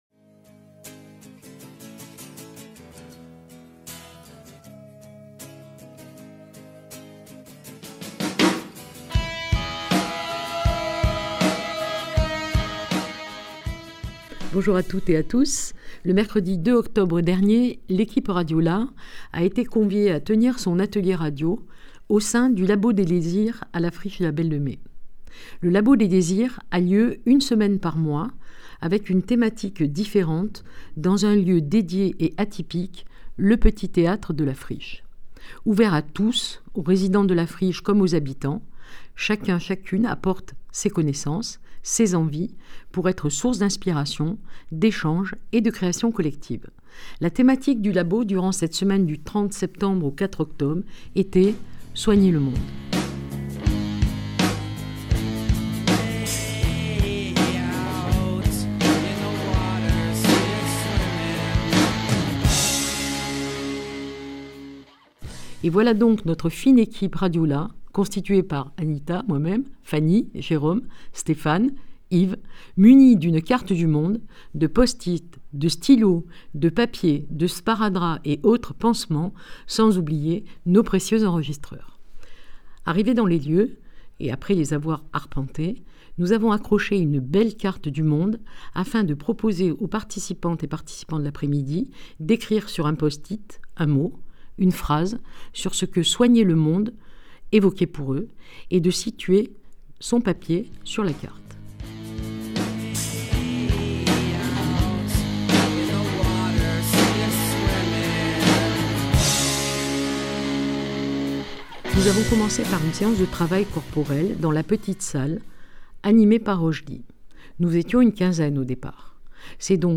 En octobre dernier , l’équipe Radio Là a été conviée à tenir son atelier radio au sein du Labo des Désirs à la Friche de la Belle de Mai, dont la thématique était… Soigner le Monde !
Ce sont tous ces témoignages, interviews, textes lus et poésie qui sont à l’origine de cette émission et en construisent sa progression: